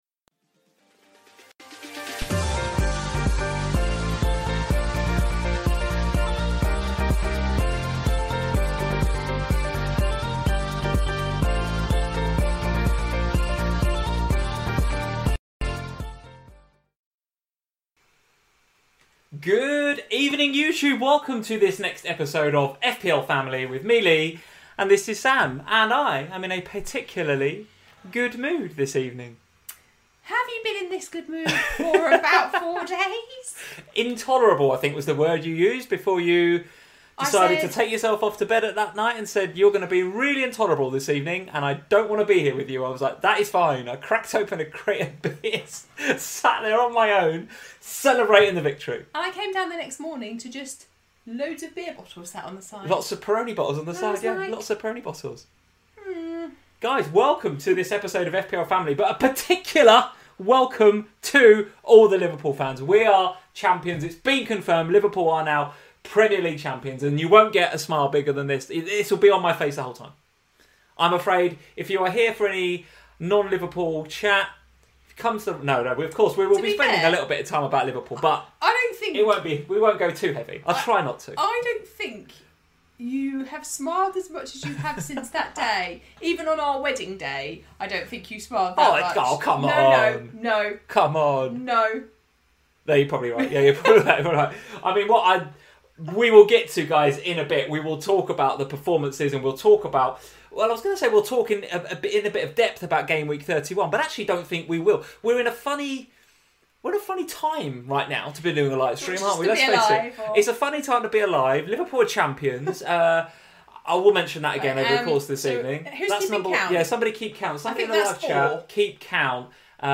Welcome to FPL Family, a chat show dedicated to all things Fantasy Premier League. So Liverpool finally wrap up the title, thanks to City's defeat at Chelsea - so what now for their FPL assets?